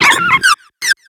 Cri de Rozbouton dans Pokémon X et Y.